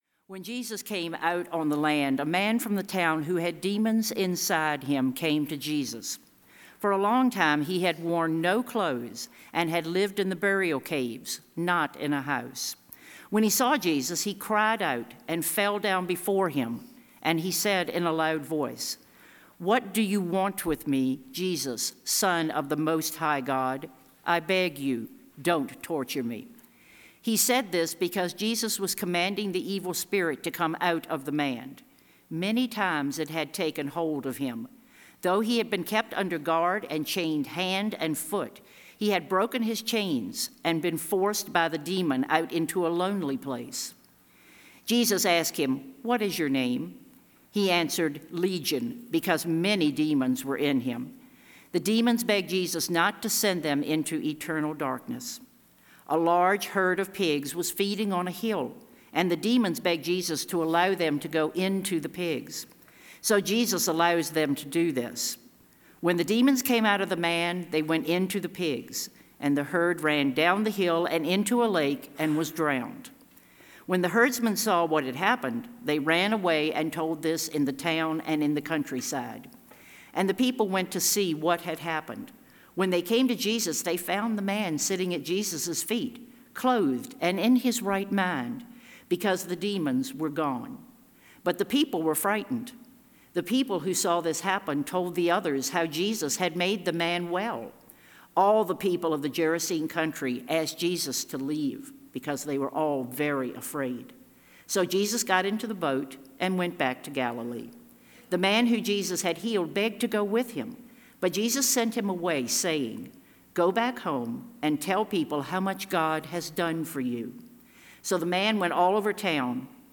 06-19-Scripture-and-Sermon.mp3